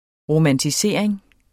Udtale [ ʁomantiˈseˀɐ̯eŋ ]